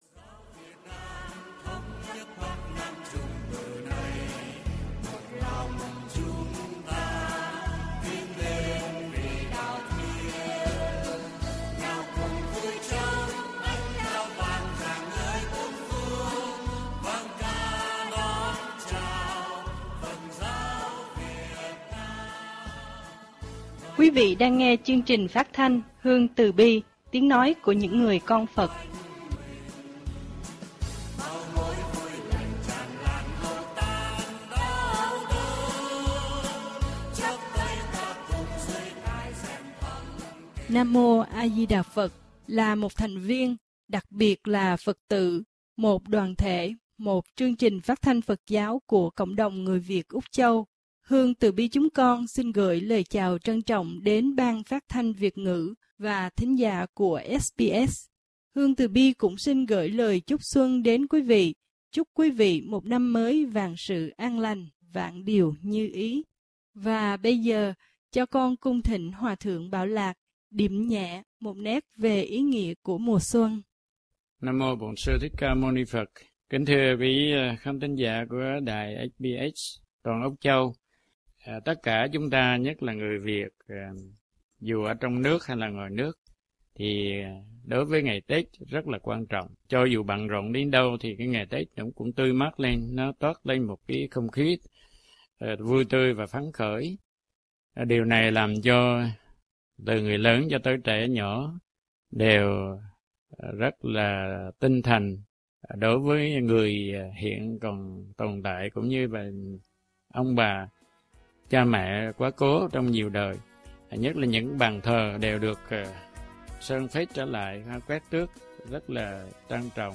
Mỗi dịp Tết đến Xuân về, những người con Phật lại một lần được lắng lòng mình trước những thông điệp Xuân đầy ý nghĩa của những tu sĩ Phật Giáo, trụ trì các chùa và tu viện từ khắp Úc quốc.